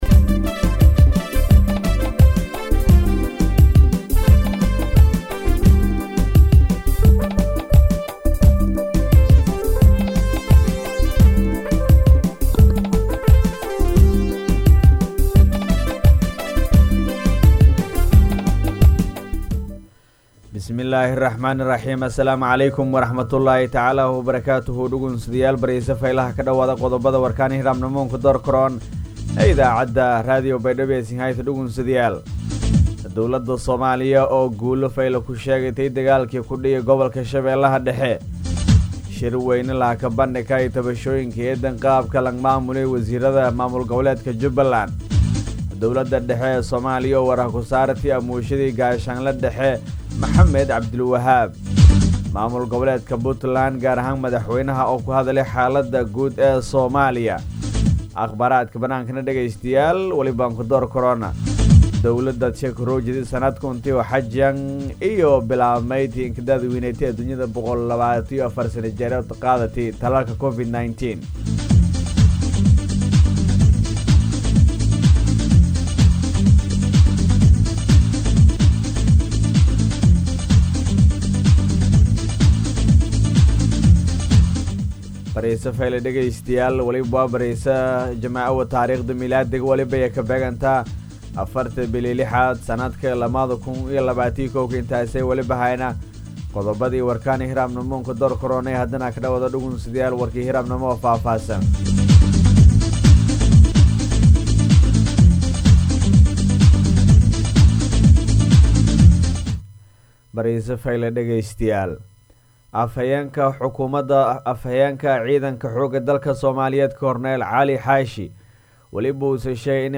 BAYDHABO–BMC:–Dhageystayaasha Radio Baidoa ee ku xiran Website-ka Idaacada Waxaan halkaan ugu soo gudbineynaa Warka subax ee ka baxay Radio Baidoa.